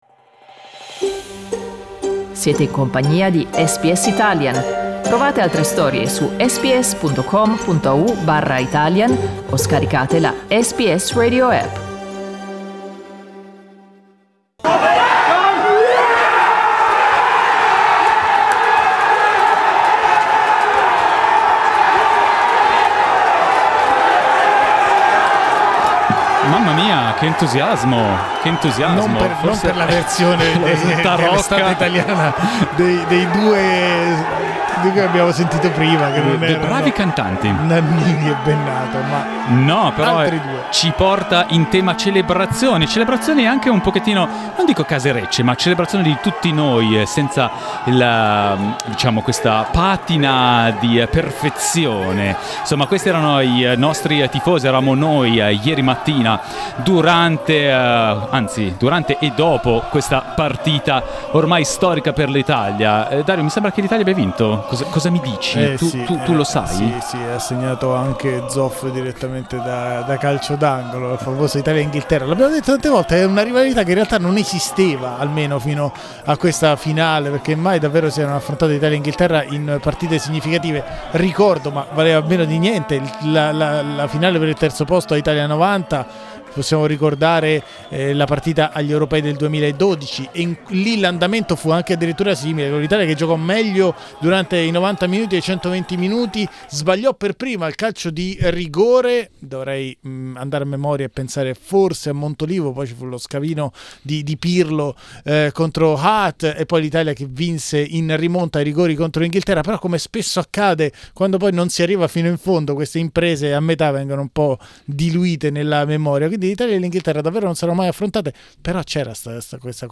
La storica vittoria della nazionale italiana nella finale di Wembley ha assunto un significato speciale per chi tifa azzurro dall'Australia. Abbiamo raccolto le testimonianze di alcune persone che hanno seguito l'evento a Lygon street, Melbourne.